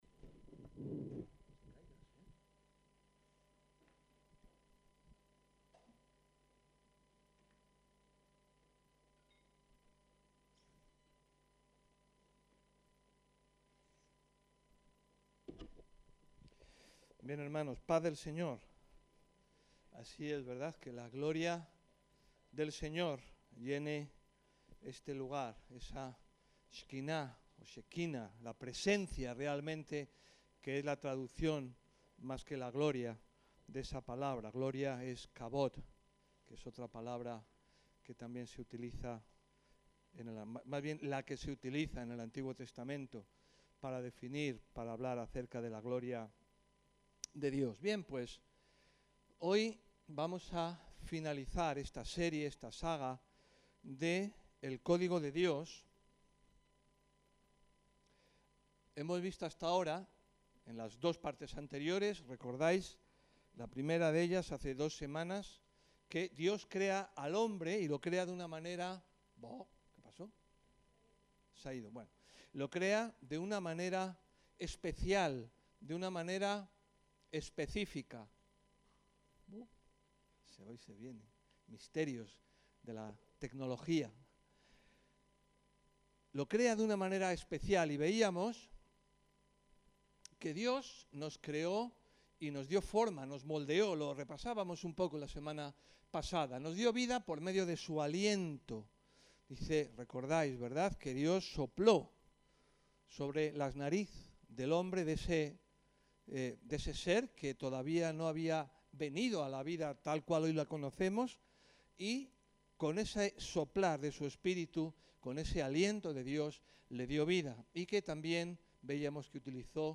Aquí está el soporte documental de la predicación: El Código de Dios – 03 Dios en el hombre